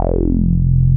RUBBER F2 M.wav